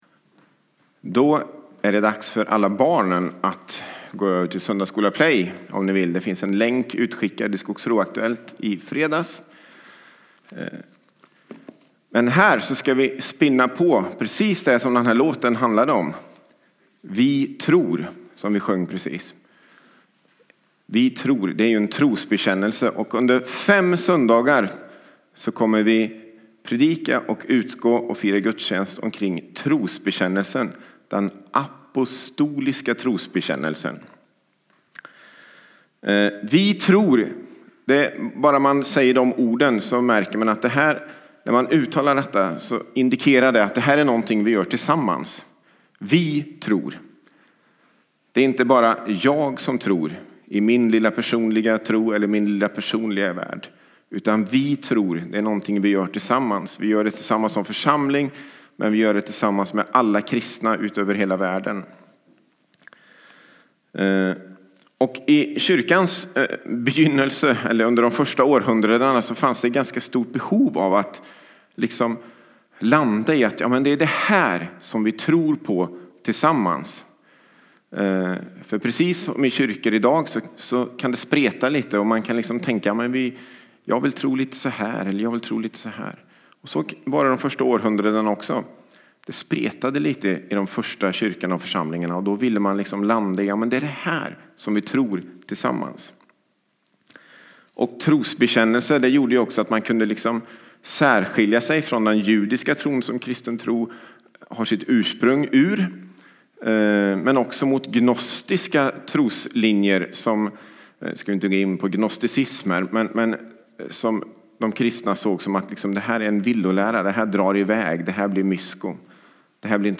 Predikan
A predikan from the tema "Fristående HT 2018."